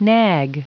Prononciation du mot nag en anglais (fichier audio)
Prononciation du mot : nag